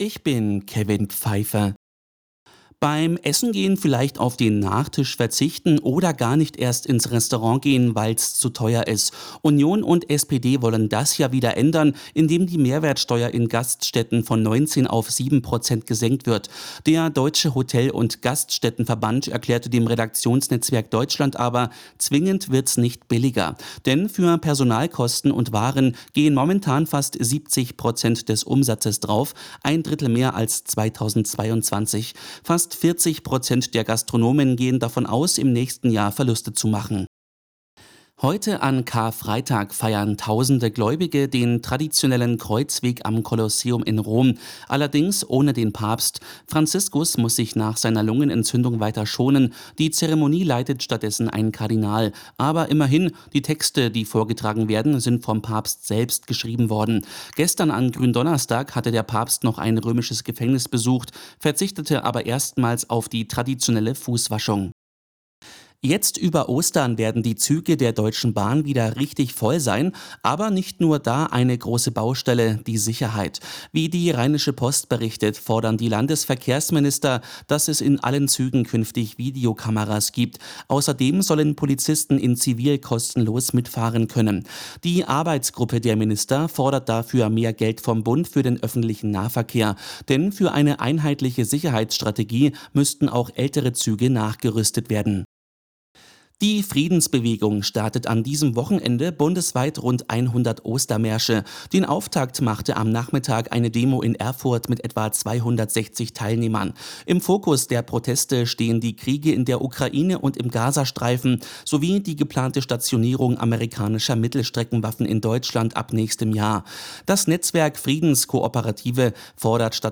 Die Radio Arabella Nachrichten von 19 Uhr - 06.06.2025